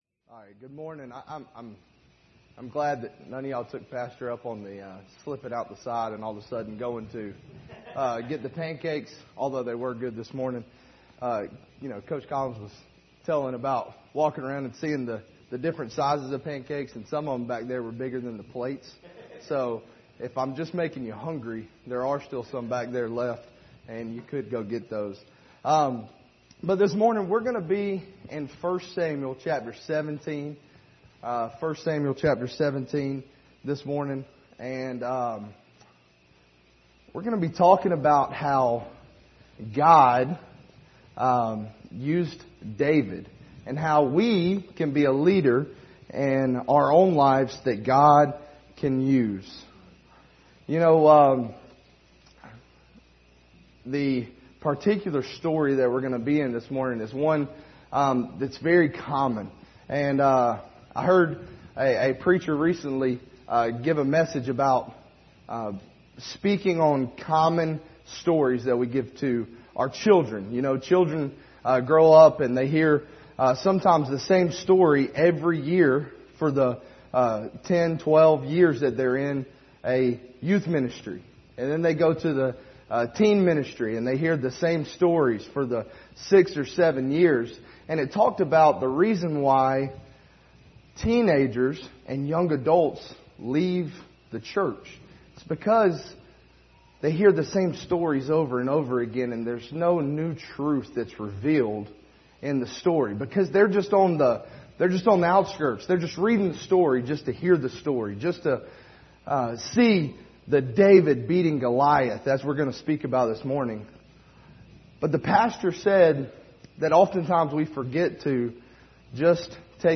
Service Type: Sunday School Hour